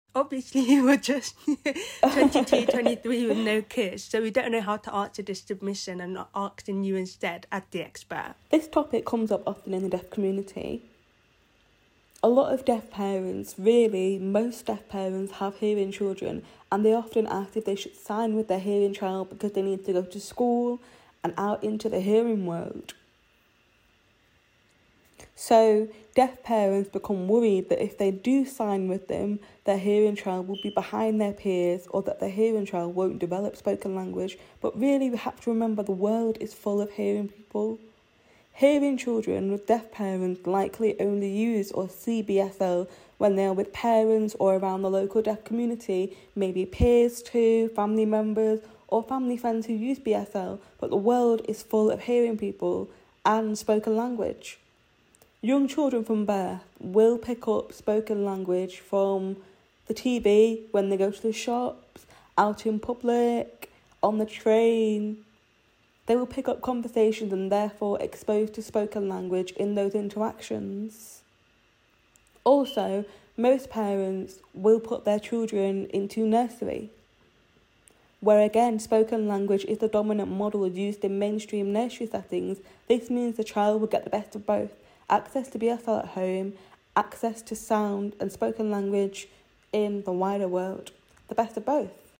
We loved this conversation and we hope you will do too!